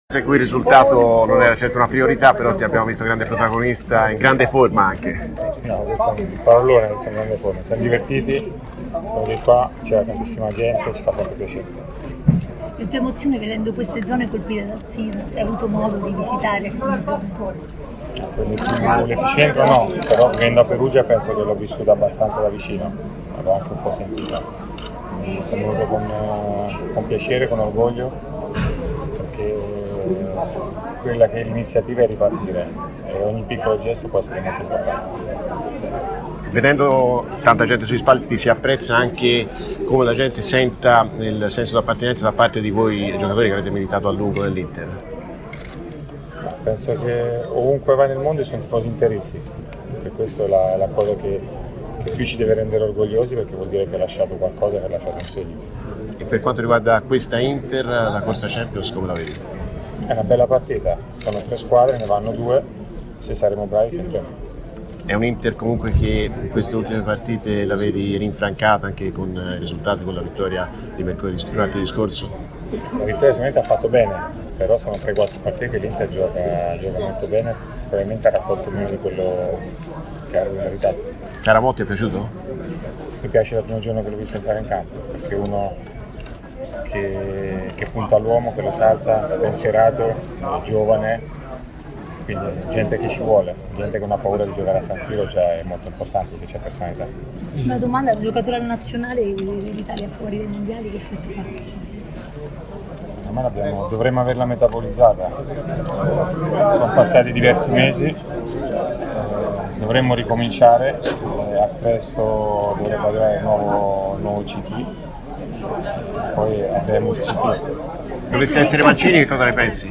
Marco Materazzi, ex giocatore, intervistato
a margine dell'appuntamento a Tolentino di Inter Forever